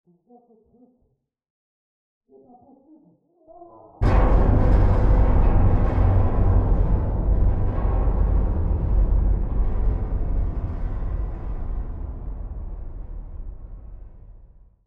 Commotion9.ogg